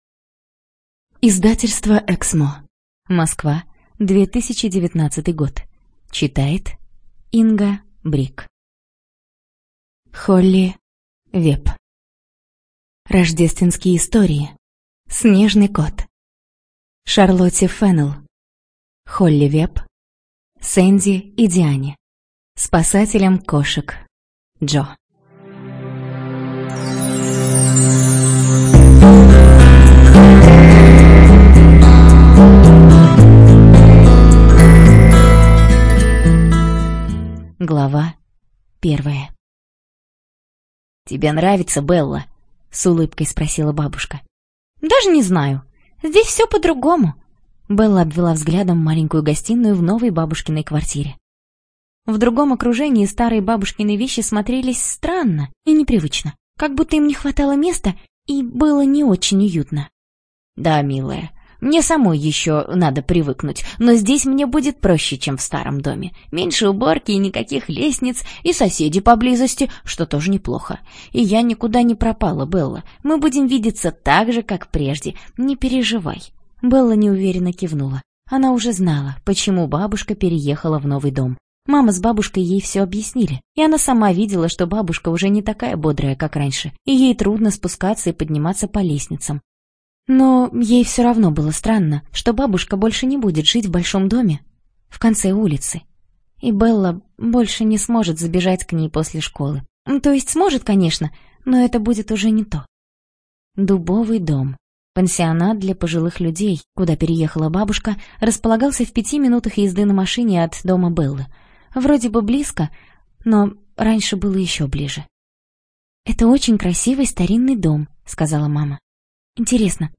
ЖанрДетская литература, Сказки
Студия звукозаписиЭКСМО